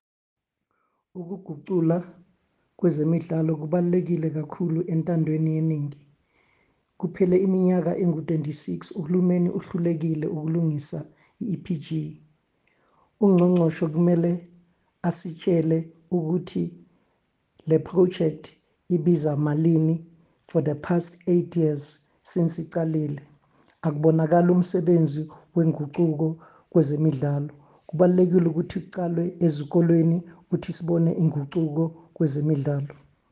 Issued by Tsepo Mhlongo MP – DA Shadow Minister of Sports, Arts & Culture
Please find an attached soundbite by in